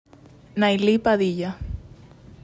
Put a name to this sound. AUDIO PRONUNCIATION: